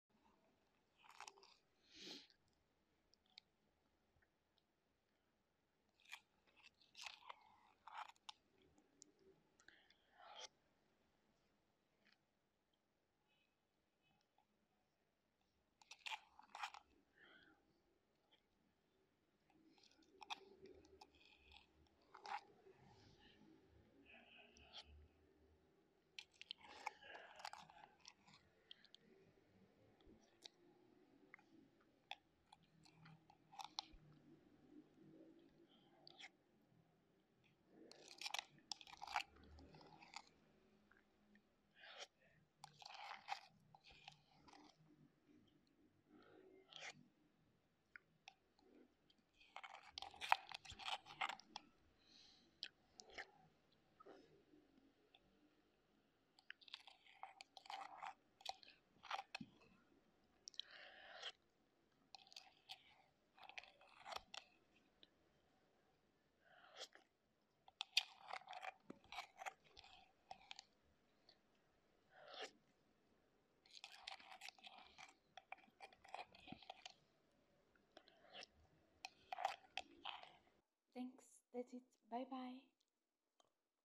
Mango-flavored Shaved Ice 🧊🥶 - Mukbang Food ASMR😋😋 - Part 8